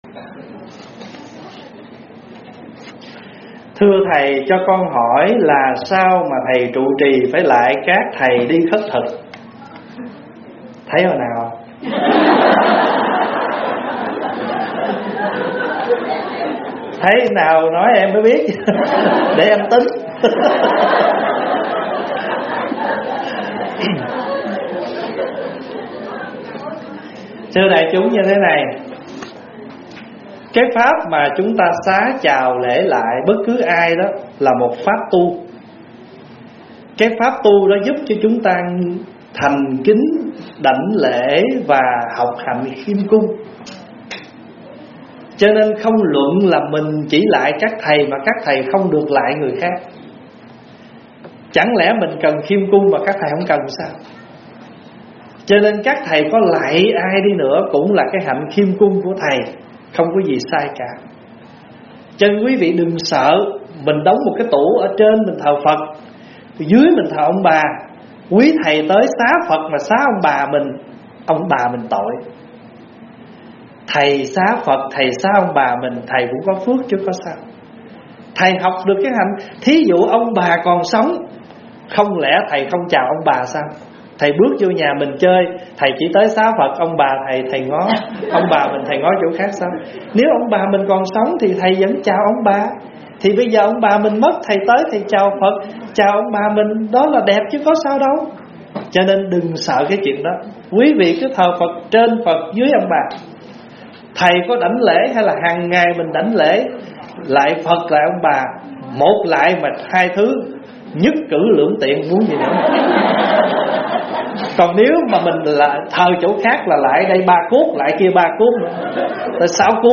Vấn đáp Học Hạnh Khiêm Cung - ĐĐ.